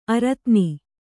♪ aratni